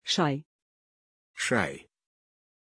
Aussprache von Shay
pronunciation-shay-ru.mp3